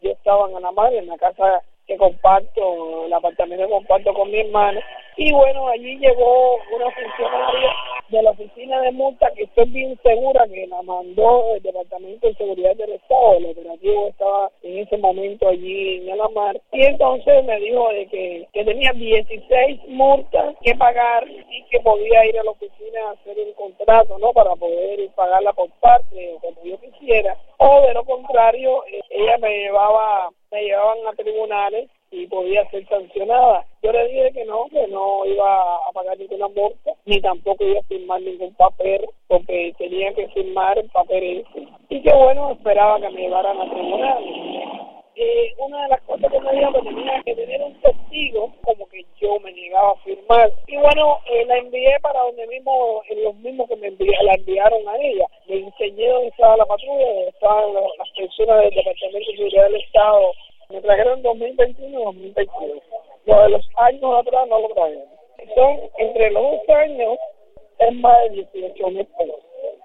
Declaraciones de Berta Soler a Radio Martí